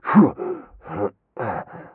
m_pain_13.ogg